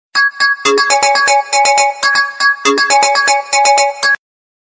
SMS Tone